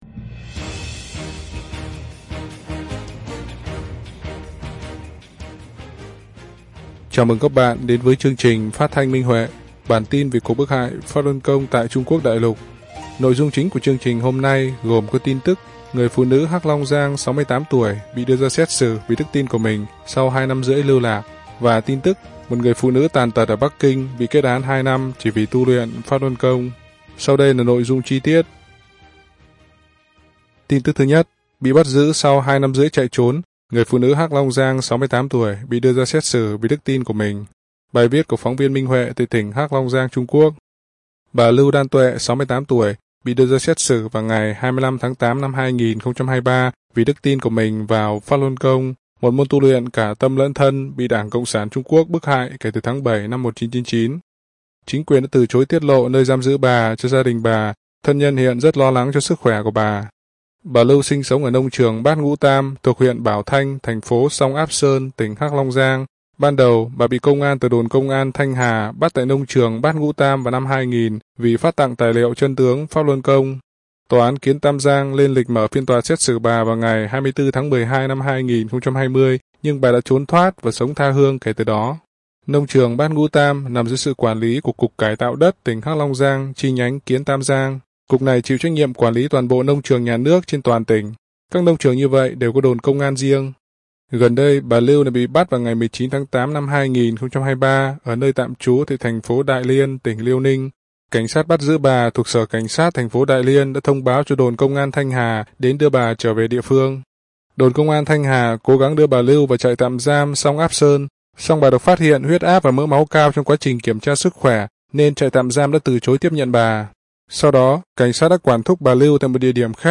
Chương trình phát thanh số 33: Tin tức Pháp Luân Đại Pháp tại Đại Lục – Ngày 14/9/2023